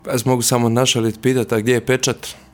ZAGREB - U ekskluzivnom intervjuu za Media servis predsjednik Mosta i potpredsjednik Sabora Božo Petrov govorio je o slučaju Agrokor ali i Vladi Andreja Plenkovića.